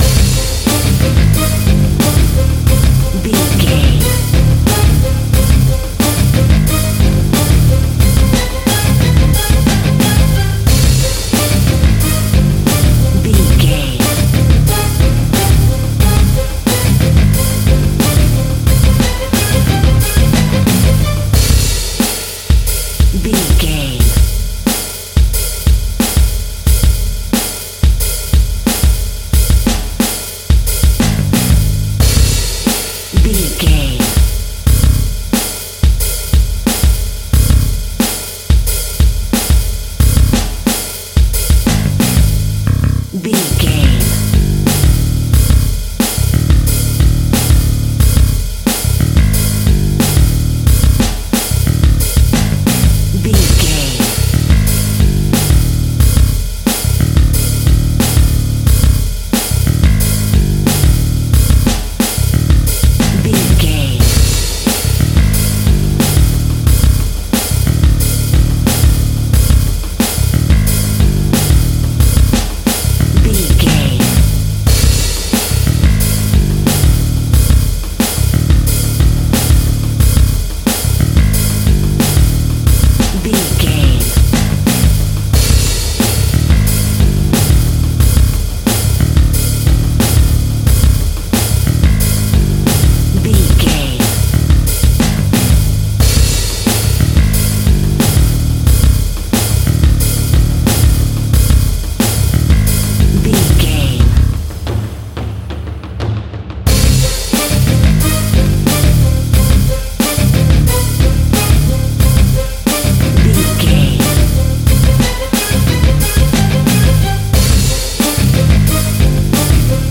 Dramatic Action Music.
In-crescendo
Aeolian/Minor
Fast
tension
ominous
dark
eerie
strings
drums
electric guitar
bass guitar
viola
orchestral instruments